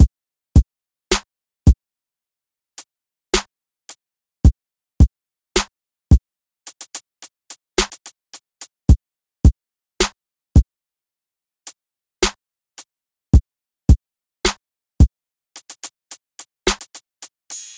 鼓声循环2
描述：情感钢琴循环的鼓声
Tag: 108 bpm Hip Hop Loops Drum Loops 2.99 MB wav Key : Unknown